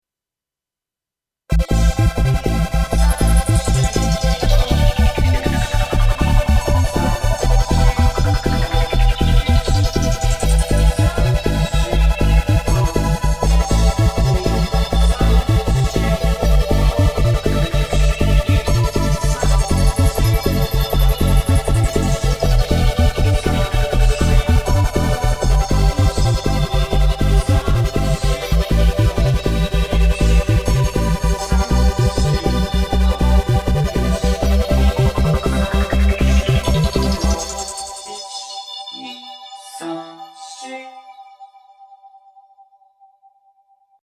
ちゃんと歌って使うとケロ声っぽくなるので楽しい。
Logicで同期して多重録音です。リズムトラックを勢いで弾いて,上物を乗せただけなのでまぁこんなものかなと。ちなみに終始"いち・にぃ・さん・し・ご"と数えているのが僕です。